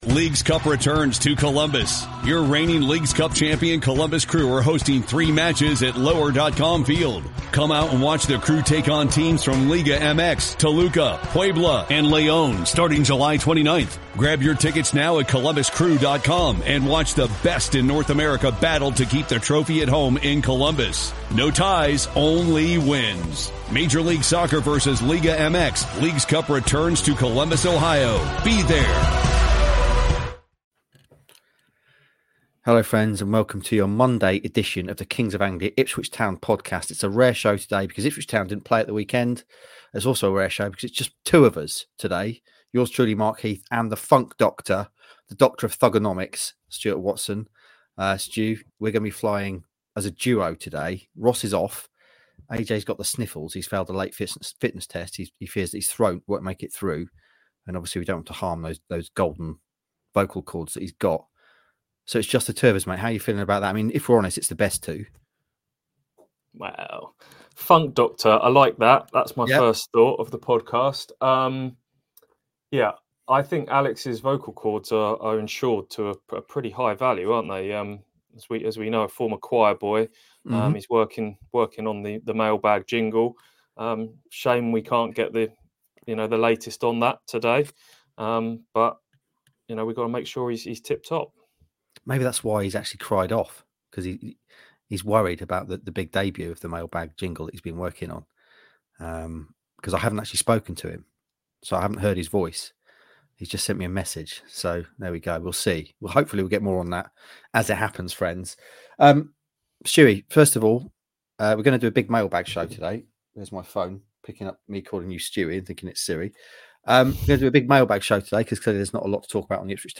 a rare two-man pod